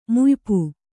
♪ muypuy